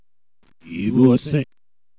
This is the sound played in reverse.
Backwards.wav